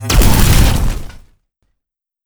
Lightning Strike.wav